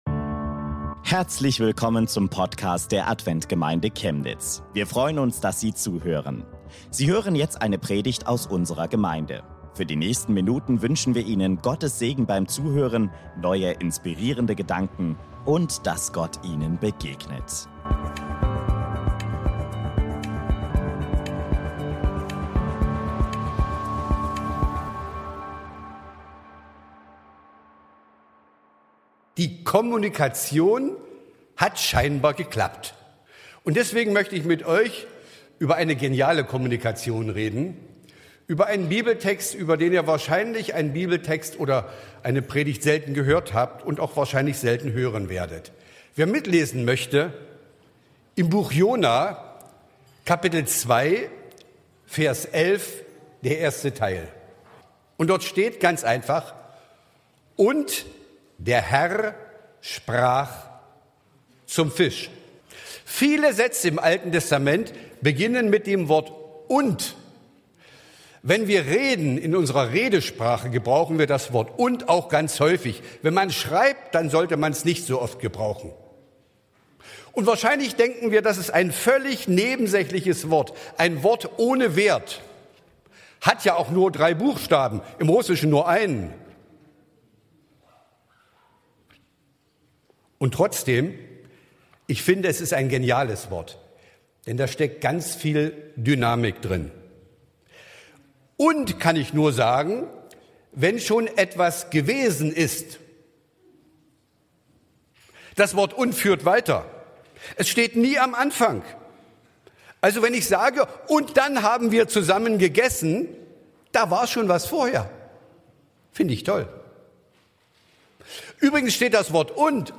Hörst du Gott in deinem Leben? ~ Adventgemeinde Chemnitz - Predigten Podcast